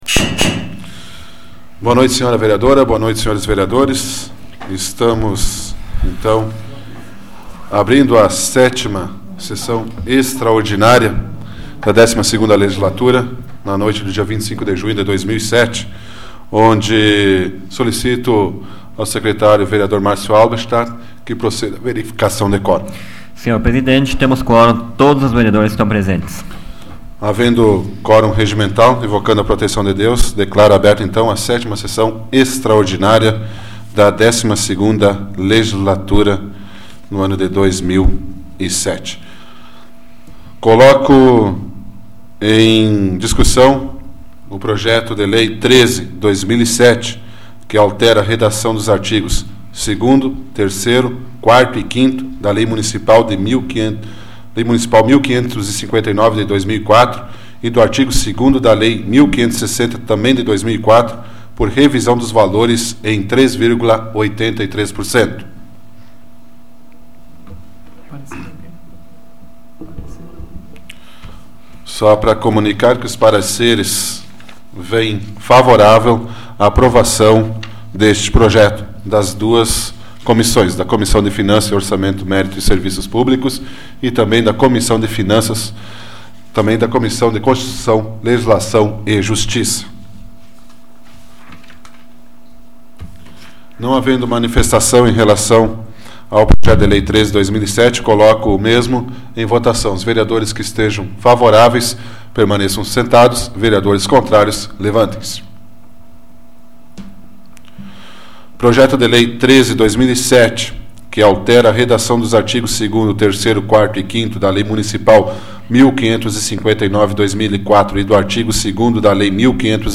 Áudio da 37ª Sessão Plenária Extraordinária da 12ª Legislatura, de 25 de junho de 2007